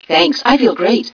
1 channel
mission_voice_idfe014.wav